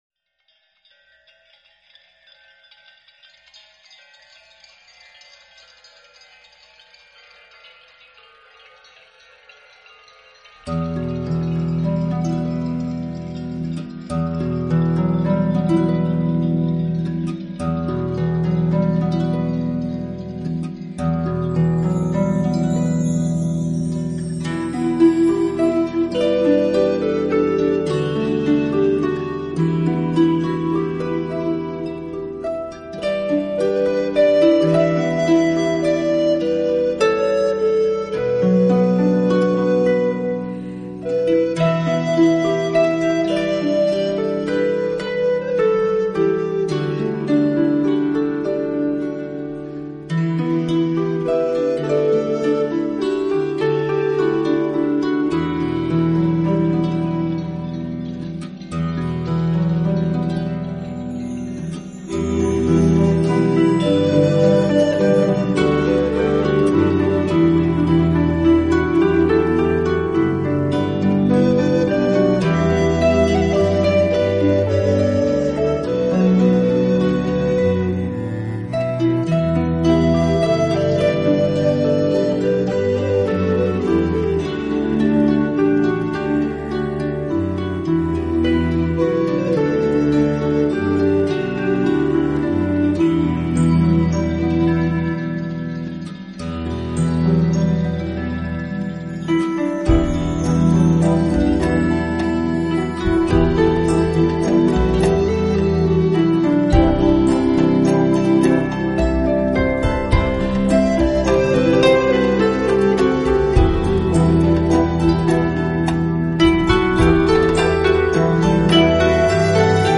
凯尔特竖琴